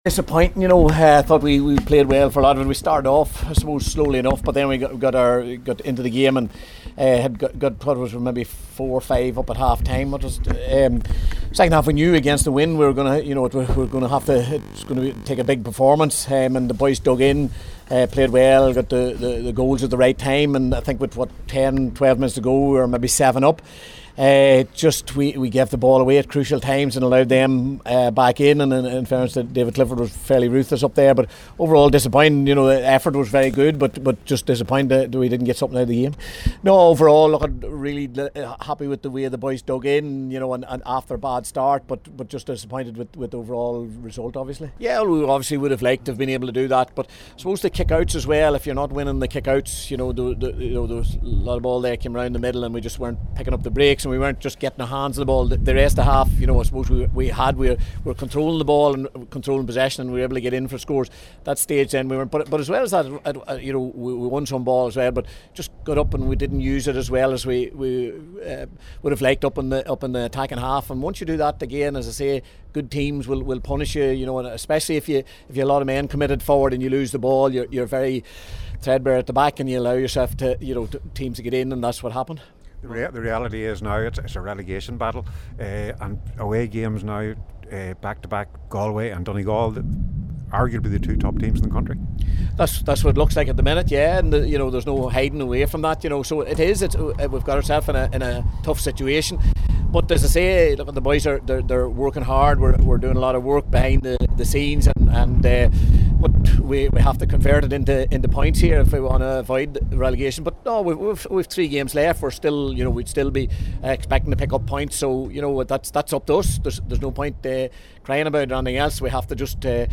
After the game Tyrone boss Malachy O’Rourke spoke to the media…